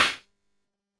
player_attack.mp3